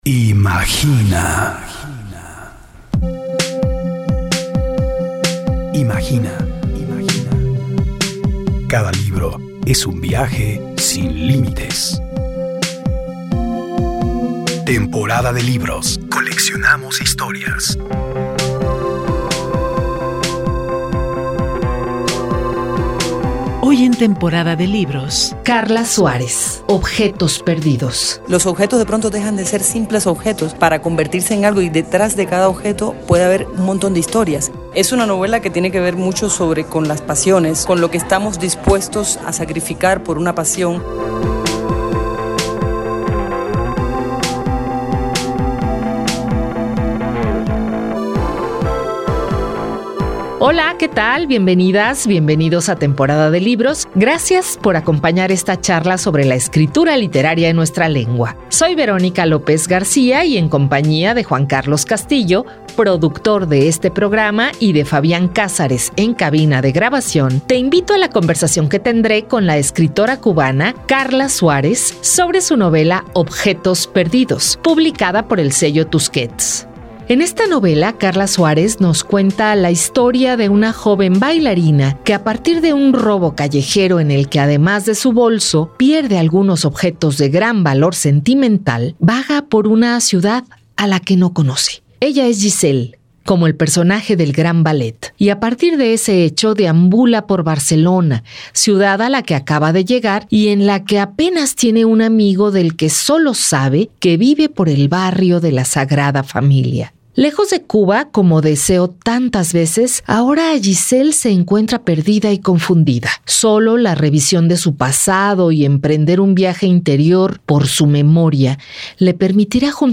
La escritora cubana, Karla Suárez, nos habla en esta emisión de su más reciente publicación, la novela "Objetos perdidos", editaba bajo el sello de Tusquets.
En esta conversación nos comparte la gestación de esta novela, editada por Tusquets, y los diferentes temas que le interesó plasmar en la historia.